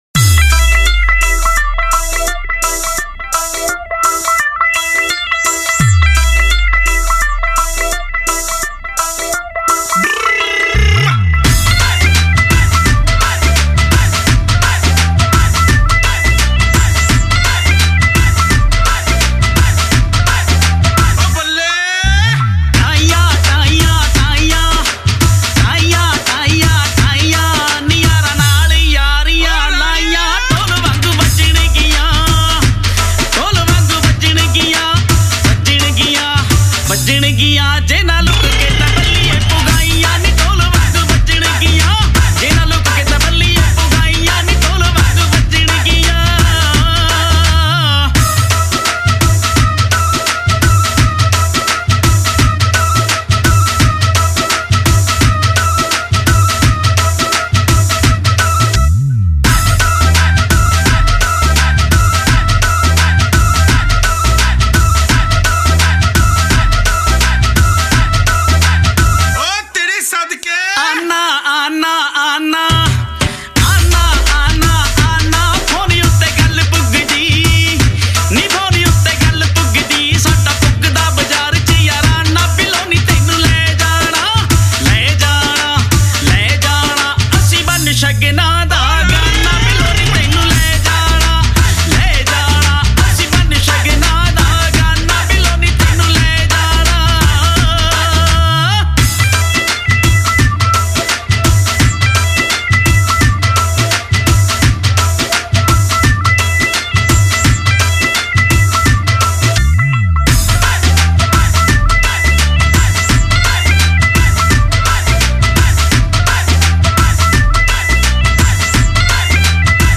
Category: UK Punjabi